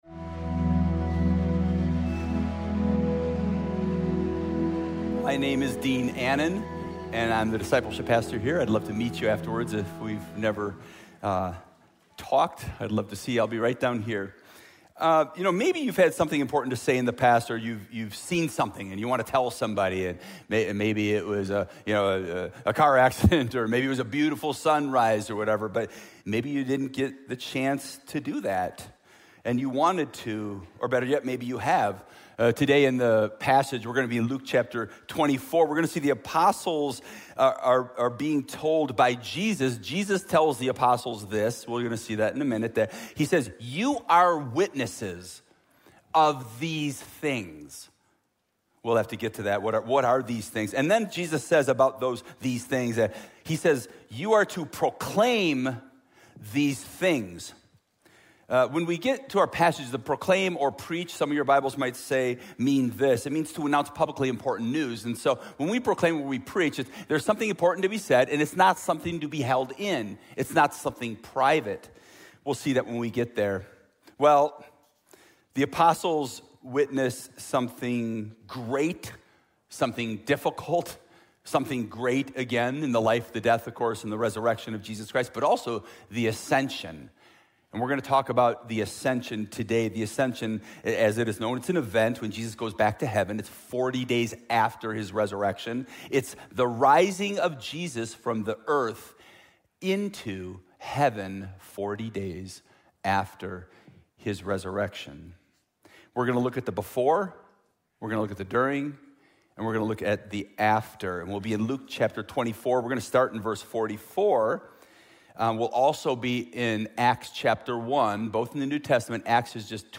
Sermons The Ascension